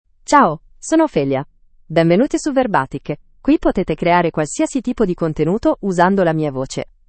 OpheliaFemale Italian AI voice
Ophelia is a female AI voice for Italian (Italy).
Voice sample
Listen to Ophelia's female Italian voice.
Female
Ophelia delivers clear pronunciation with authentic Italy Italian intonation, making your content sound professionally produced.